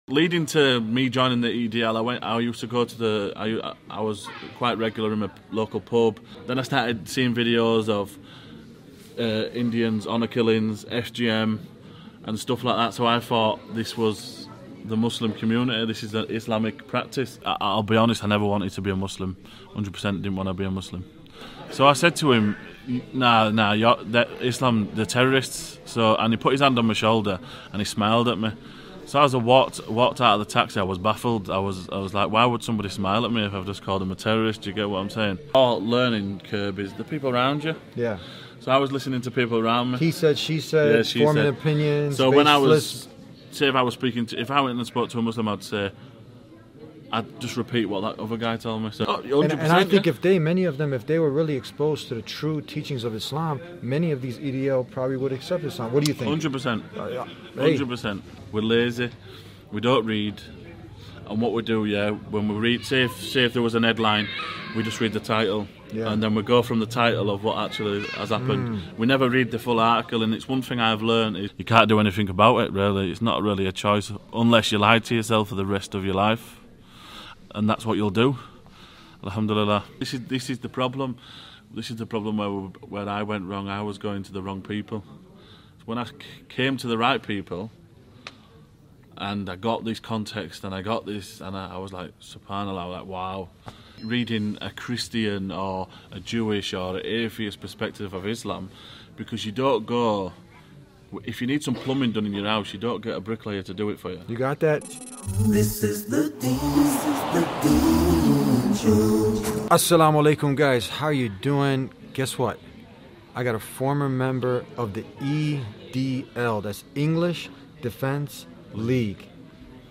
In this powerful episode filmed inside a mosque in Manchester, England, a former member of the English Defence League (EDL) shares his incredible journey from being an anti-Islam activist to accepting Islam and becoming a practicing Muslim. His story demonstrates that when sincere seekers go directly to Islamic sources rather than relying on secondhand misinformation, the truth becomes undeniable.